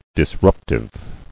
[dis·rup·tive]